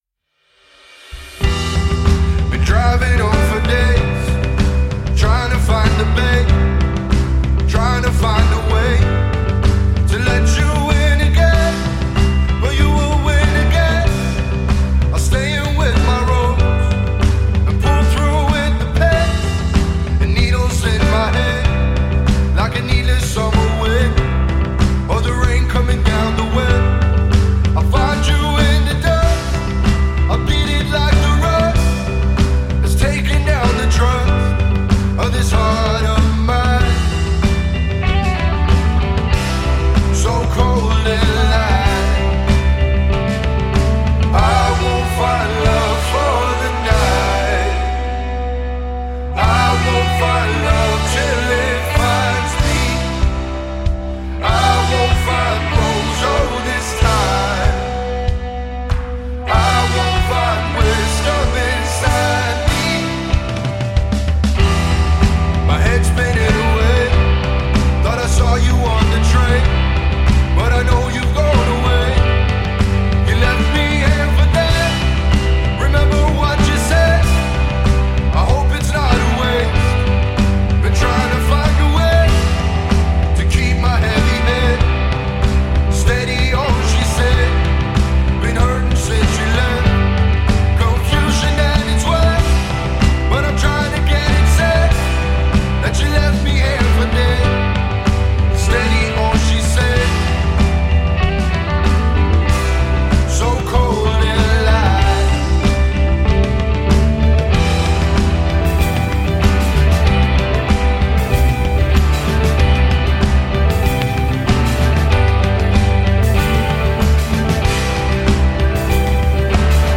alternative pop-rock band